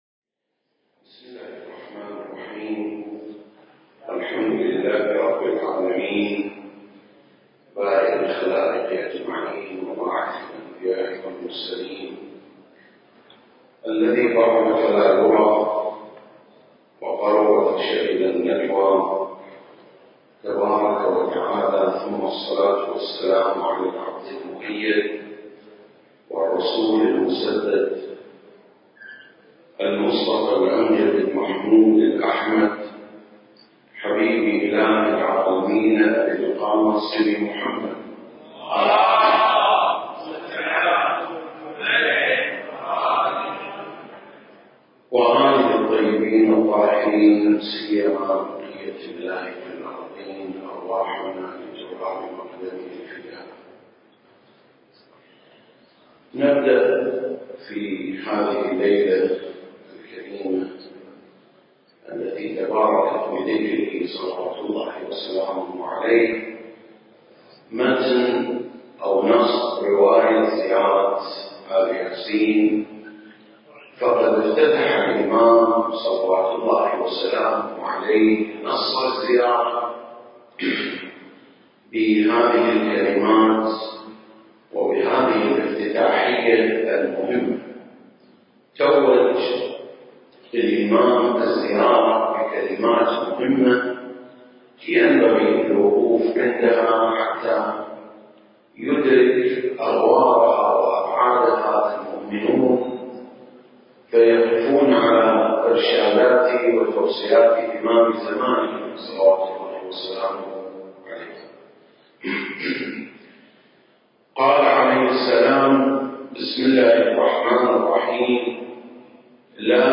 سلسلة: شرح زيارة آل ياسين (11) - بحوث في متن الراوية المكان: مسجد مقامس - الكويت التاريخ: 2021